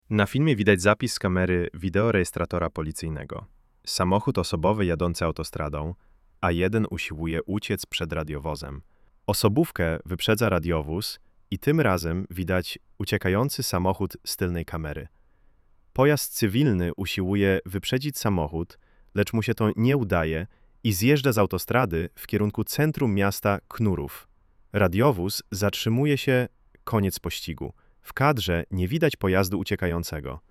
Audiodeskrypcjafilmu.mp3